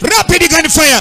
Rapid gunfire